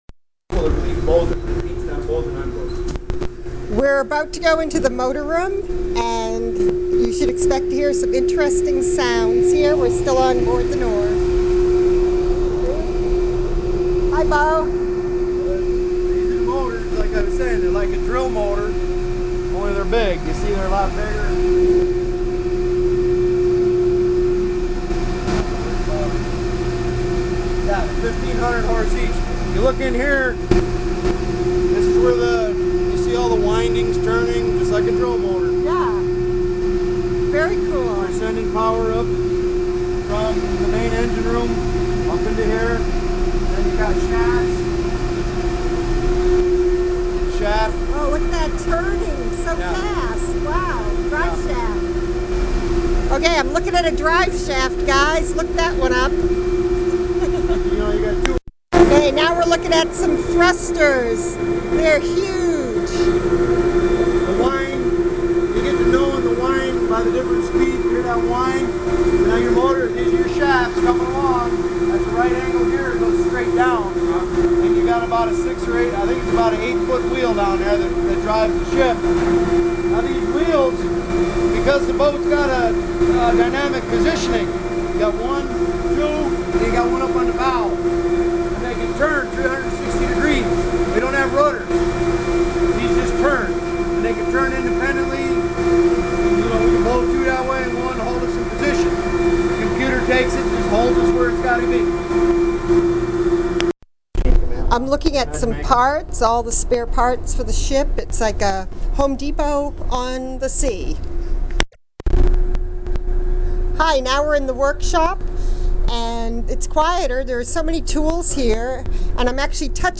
21296_engine_room.mp3